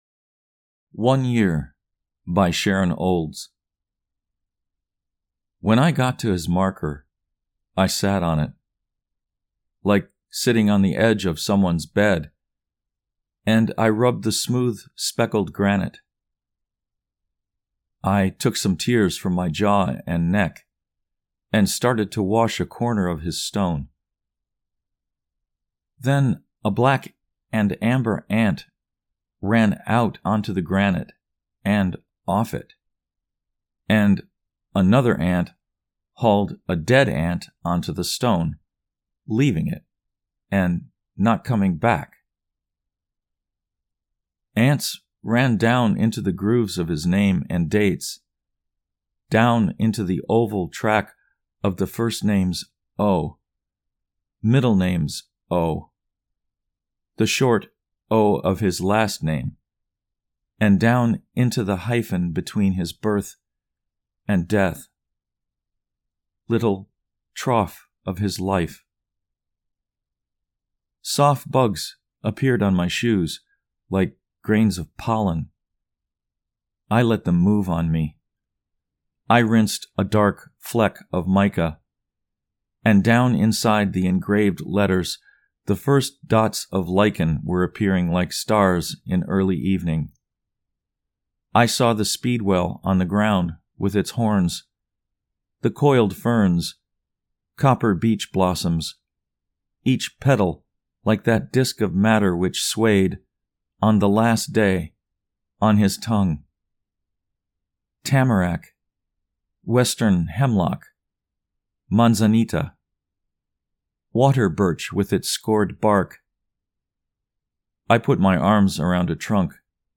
One Year © by Sharon Olds (Recitation)